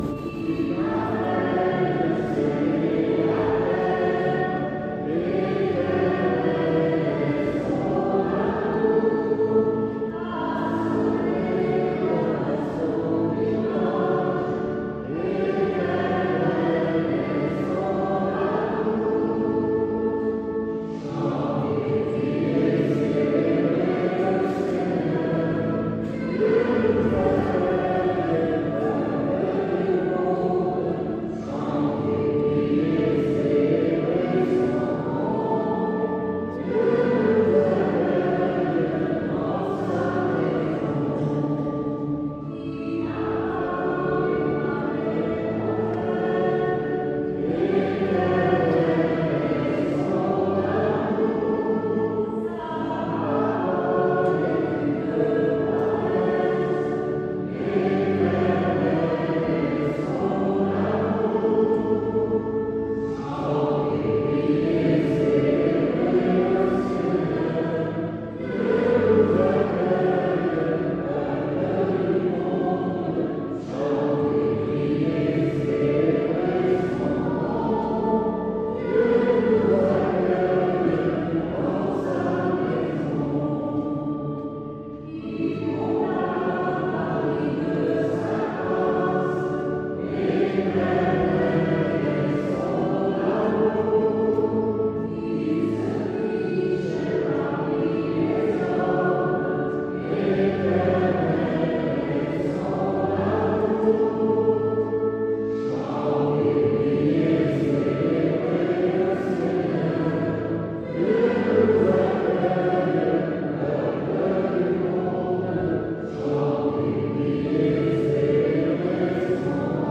Messe sonnée de la saint Hubert
L'église Notre Dame éttait presque remplie
Avé Maria (cor de chasse)